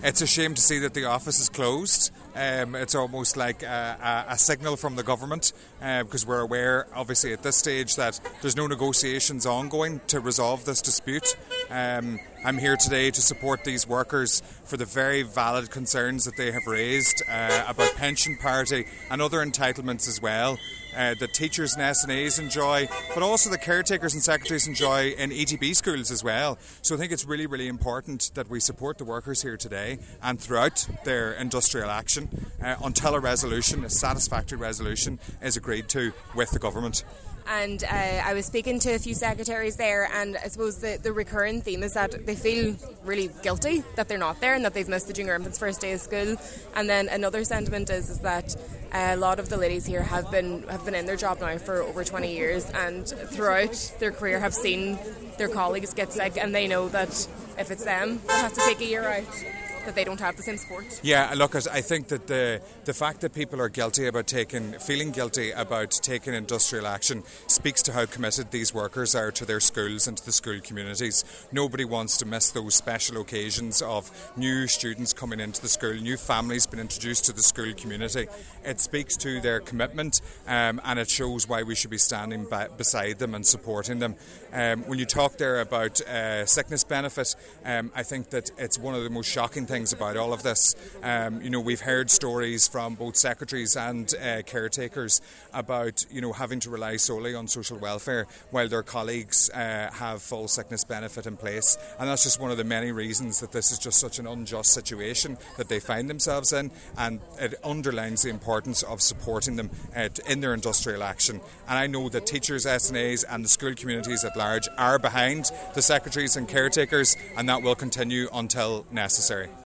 Highland Radio News spoke to those standing out in protest, and three recurring themes came into conversation: guilt, fear, and anger.
Speaking to Cllr Declan Meehan, he said the sick benefits are the most shocking element of it all: